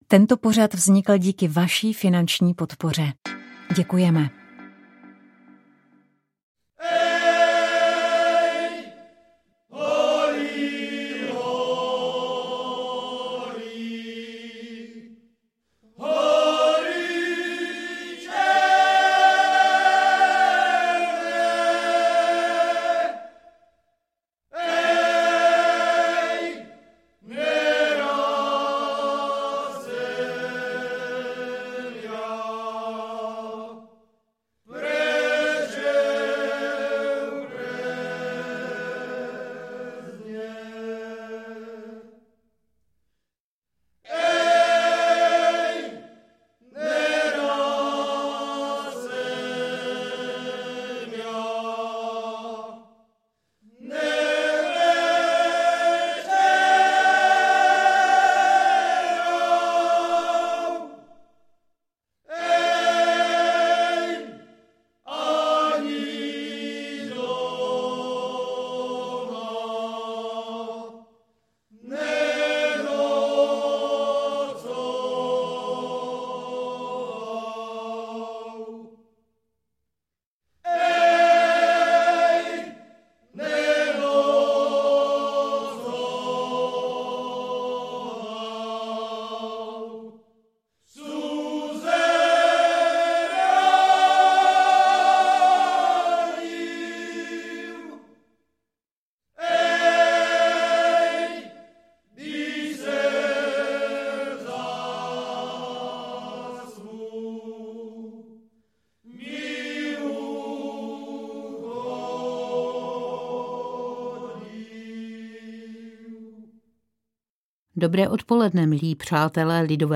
Slovem i hudebními ukázkami pozveme posluchače Proglasu do Javorníka, Kuželova a především do Velké nad Veličkou, kde se letos 17.-20. července konají tradiční Horňácké slavnosti.